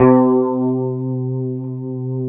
C#3 HSTRT MF.wav